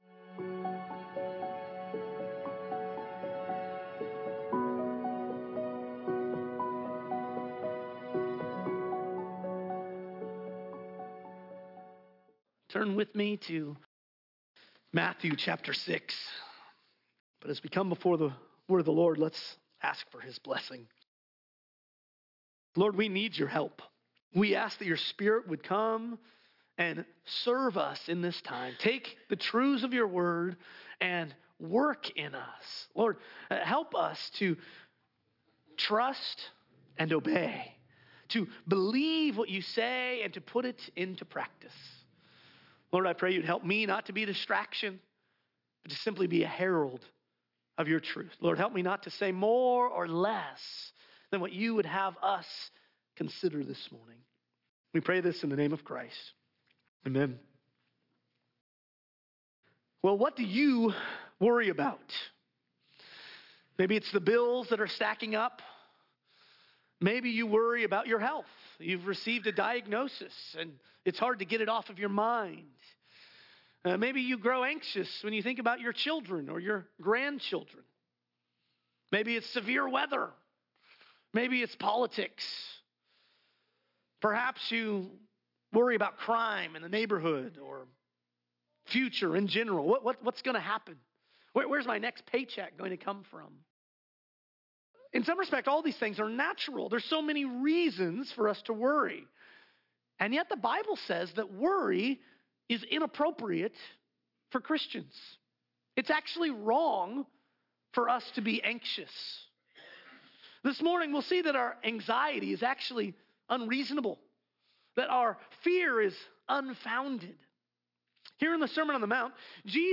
There are so many reasons to worry, but Jesus commands His followers not to worry. In this sermon